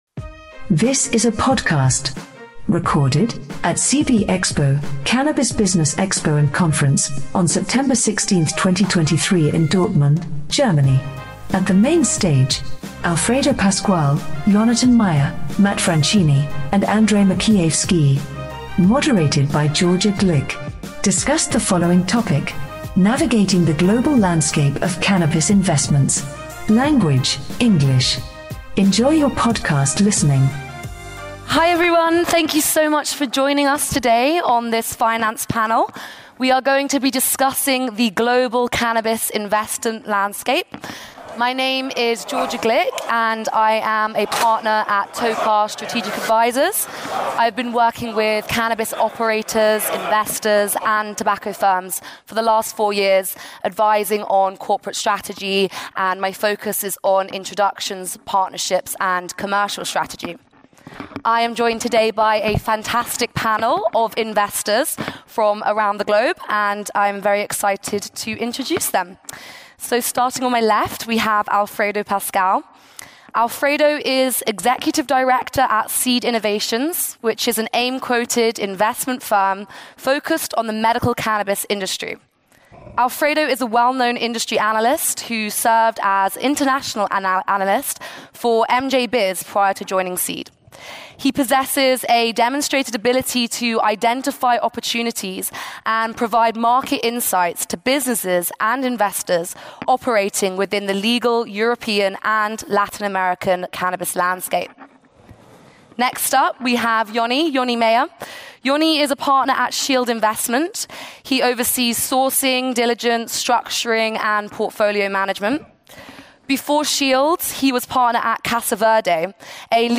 Delving into worldwide investments in both recreational and medical cannabis, this panel discussion will tackle the complexities of investing across varied regions and sectors, gleaning insights from seasoned cannabis investors around the world....